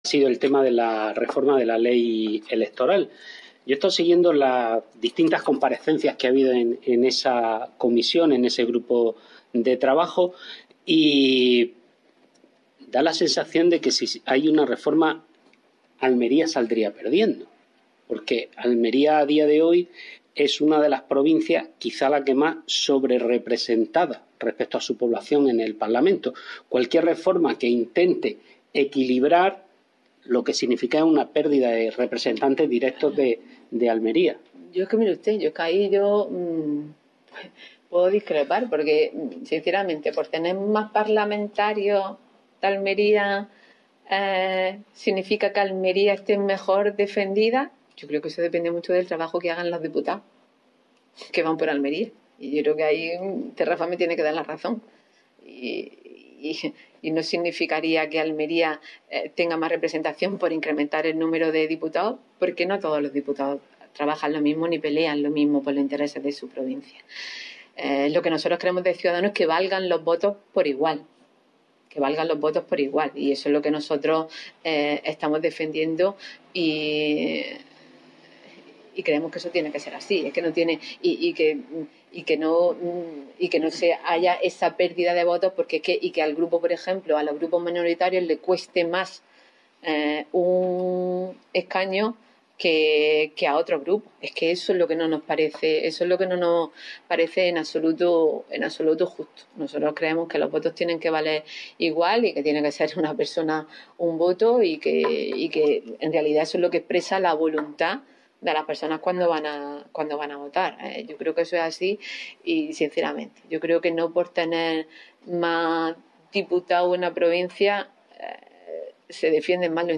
Escuche las declaraciones: